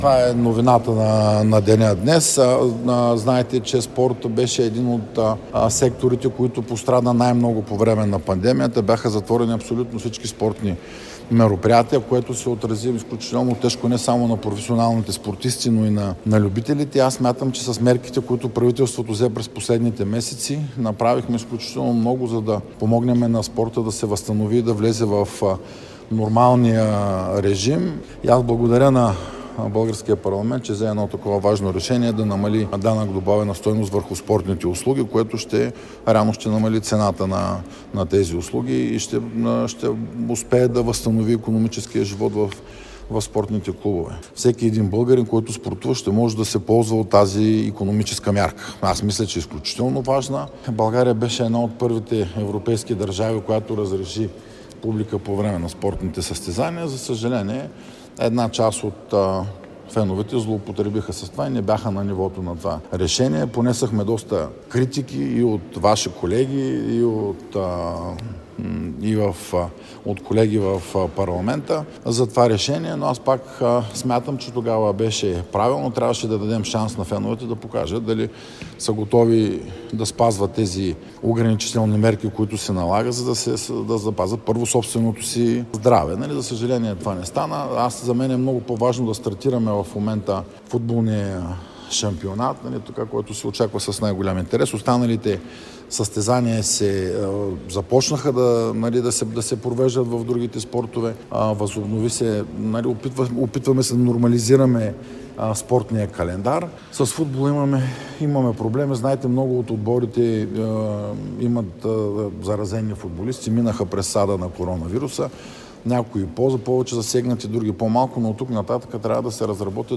След откриването на физкултурния салон на СУ “Иван Вазов” в Своге министърът на младежта и спорта Красен Кралев коментира пред журналисти актуални спортни теми.